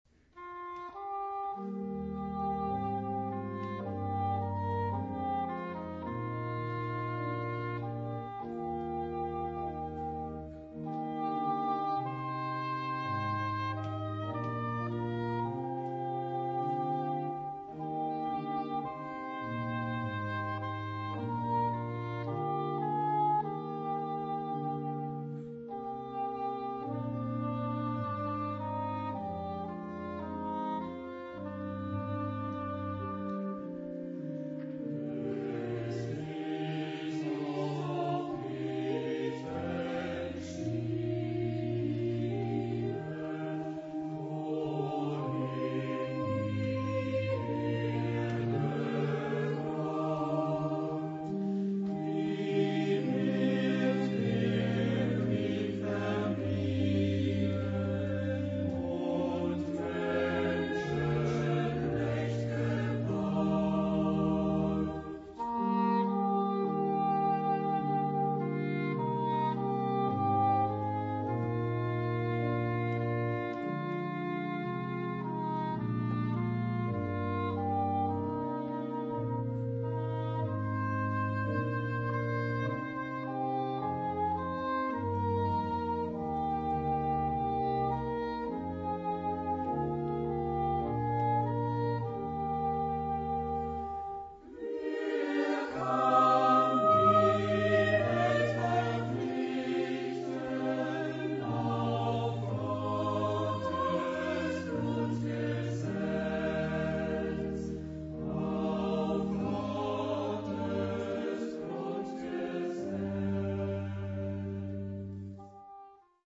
Tonalité : mi bémol majeur